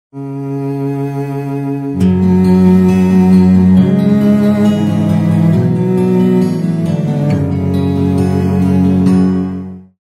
رینگتون نرم و بیکلام